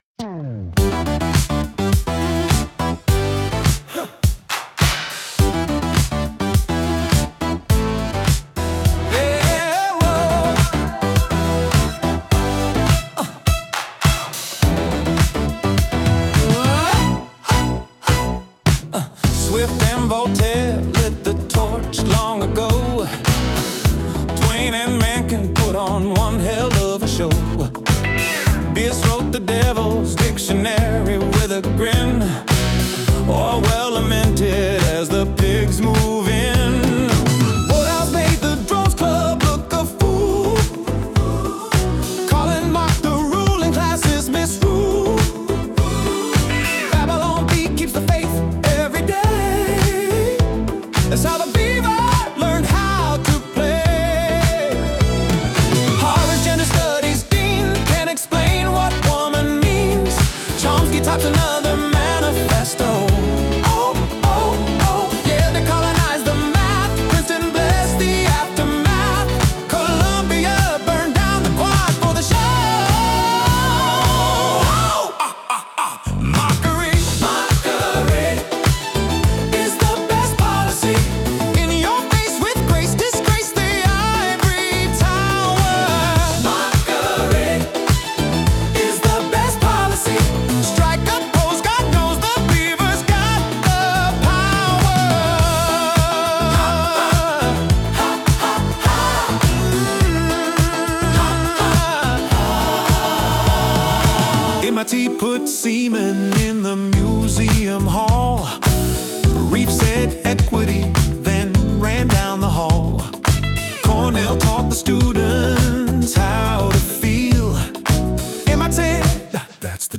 Lyrics by Claude Sonnet 4.6. Vocals and musical accompaniment by Suno.